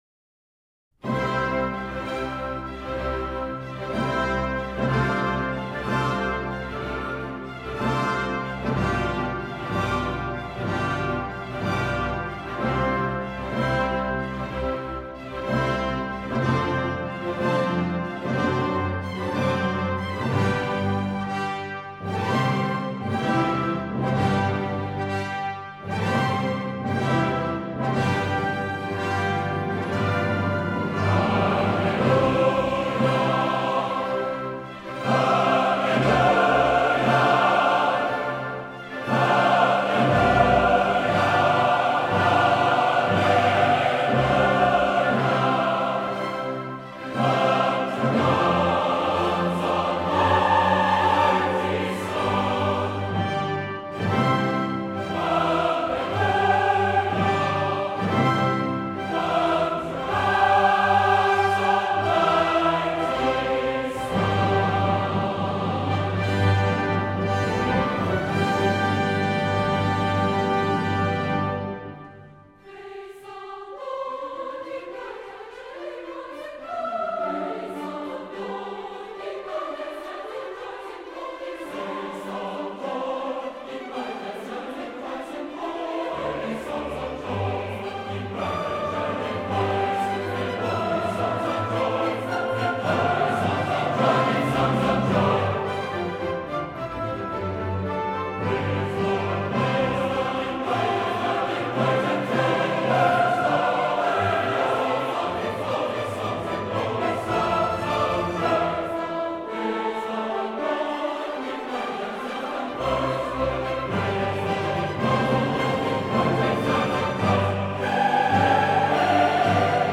Beethoven: “Hallelujah” from Mount of Olives (Atlanta Symphony Chorus, Robert Shaw, conductor)